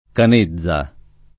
Canezza [ kan %zz a ]